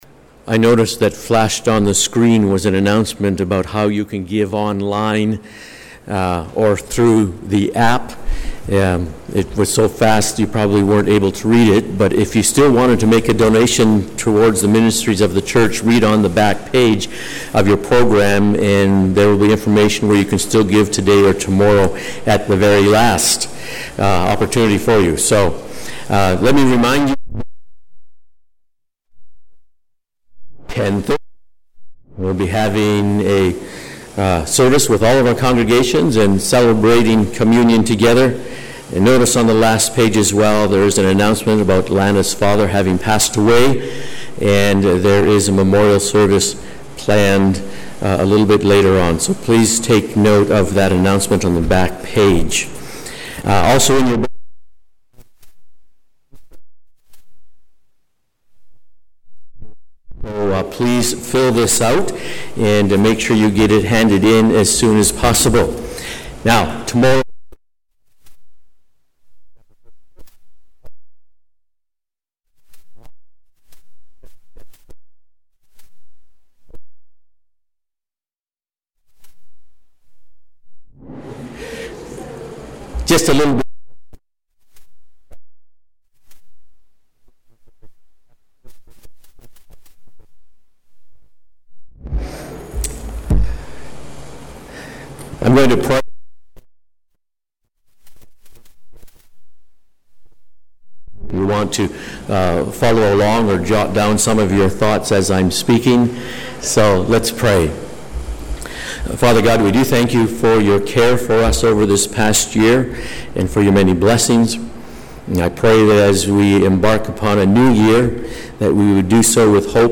Sermons | Oakridge Baptist Church
We apologize for the audio condition.